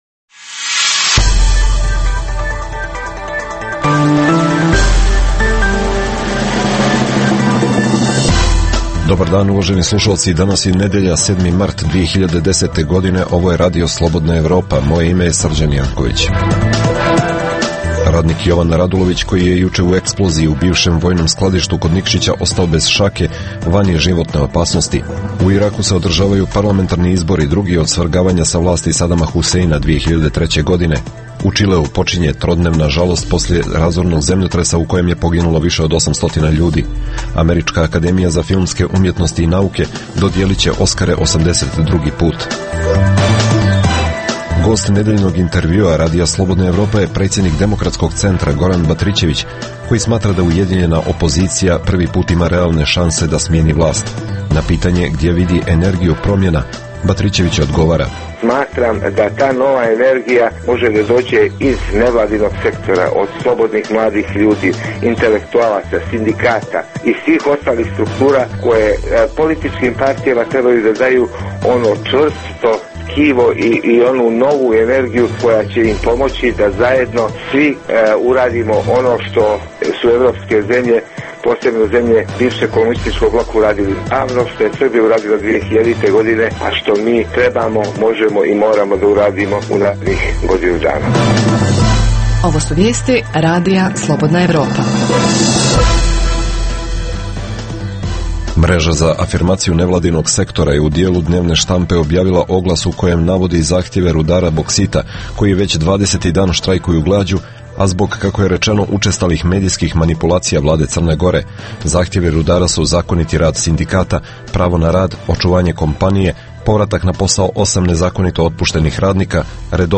Emisija namijenjena slušaocima u Crnoj Gori. Sadrži lokalne, regionalne i vijesti iz svijeta, rezime sedmice, intervju "Crna Gora i region", tematske priloge o aktuelnim dešavanjima u Crnoj Gori i temu iz regiona.